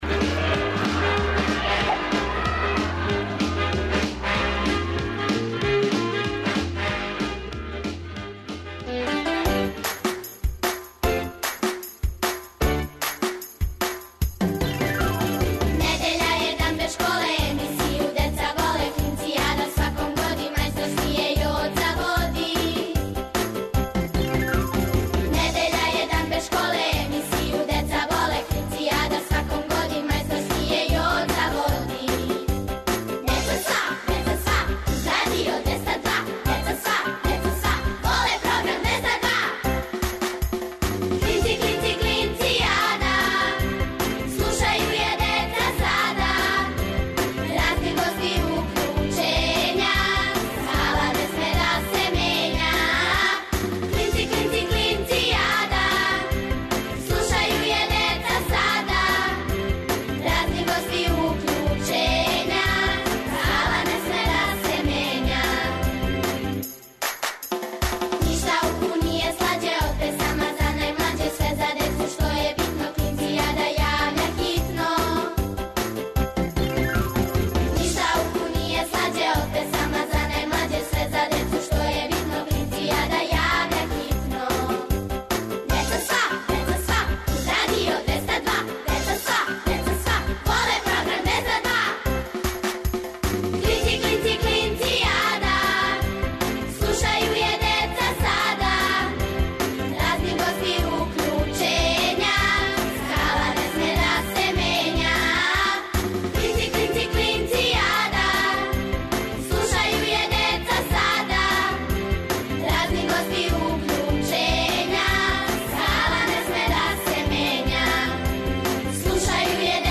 О деци за децу, емисија за клинце и клинцезе, и све оне који су у души остали деца. Сваке недеље уживајте у великим причама малих људи, бајкама, дечјим песмицама.